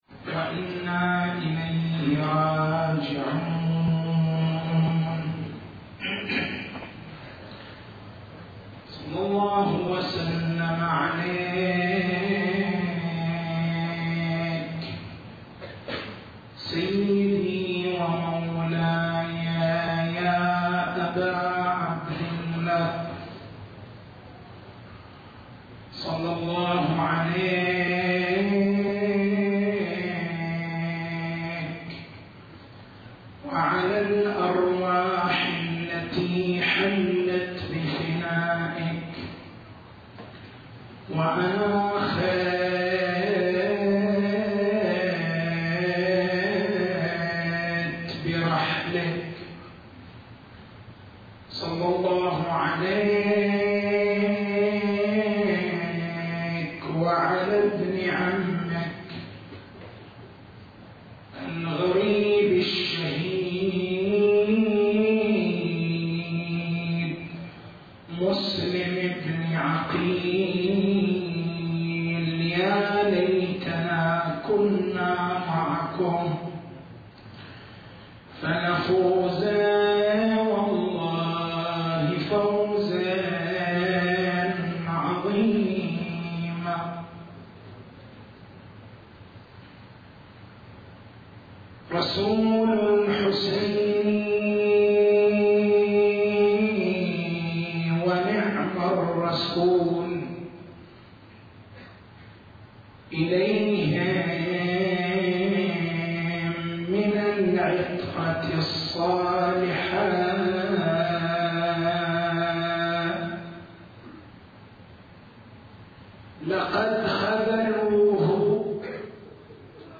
تاريخ المحاضرة: 04/01/1431 نقاط البحث: هل للعقل مرجعية في أمور الدين، أم لا؟